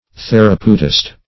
Search Result for " therapeutist" : The Collaborative International Dictionary of English v.0.48: Therapeutist \Ther`a*peu"tist\, n. One versed in therapeutics, or the discovery and application of remedies.
therapeutist.mp3